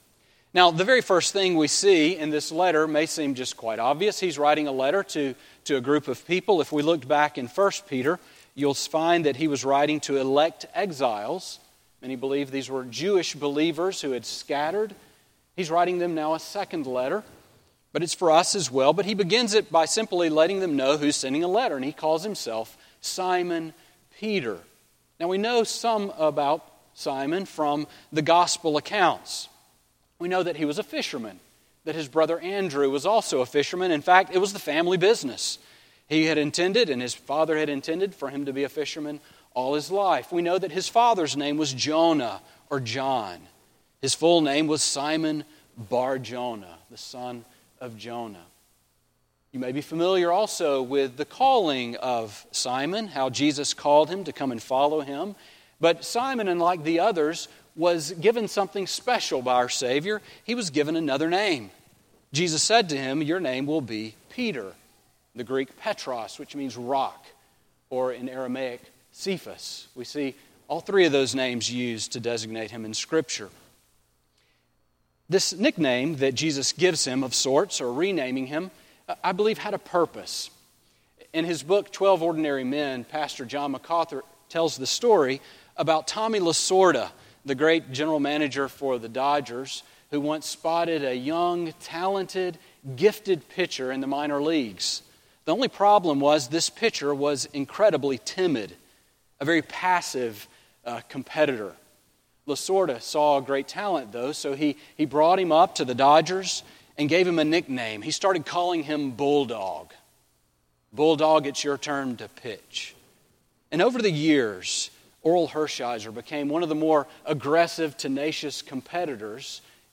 Sermon on 2 Peter 1:1-2 from January 4, 2015